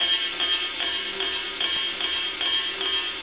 Ride 07.wav